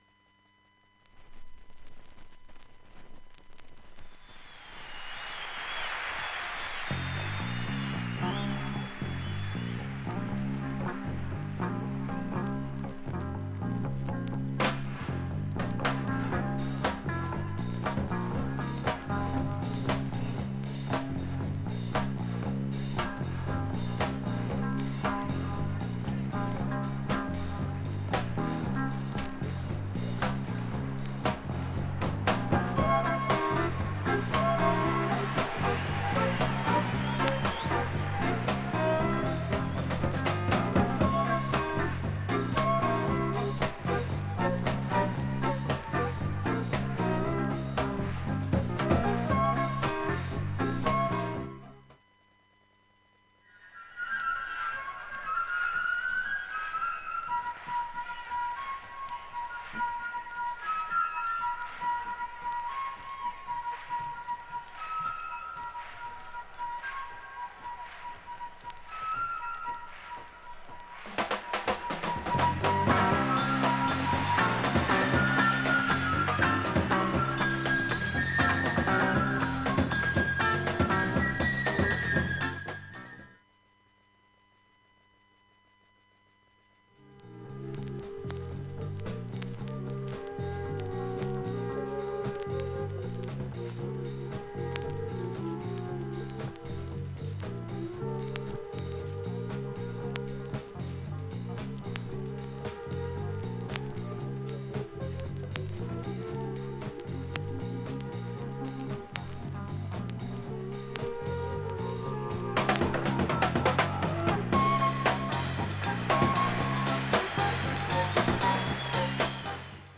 B-1始まる前に少しロードノイズが出ます。
バックチリが出ています。
ほか3回までのかすかなプツが５か所
エキサイティングなライブアルバム